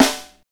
Index of /90_sSampleCDs/Northstar - Drumscapes Roland/SNR_Snares 1/SNR_Funk Snaresx